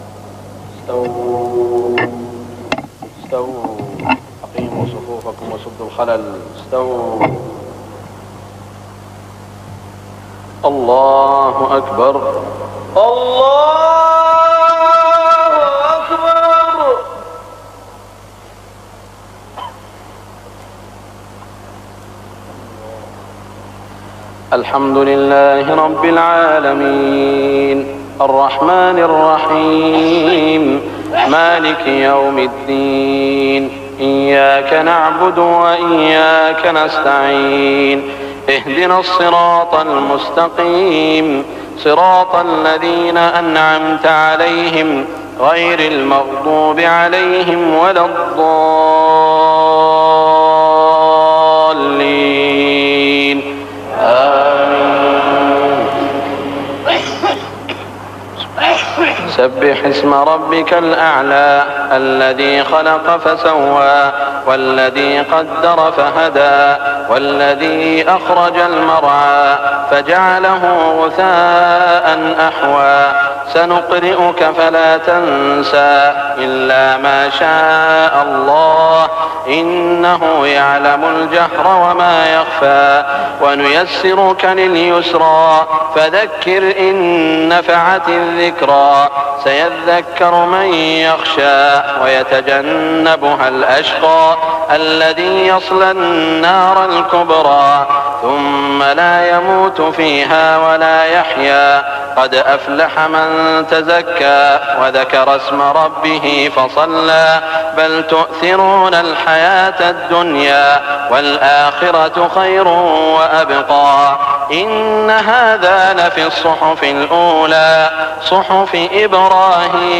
صلاة الجمعة 7-1-1417 سورتي الأعلى و الغاشية > 1417 🕋 > الفروض - تلاوات الحرمين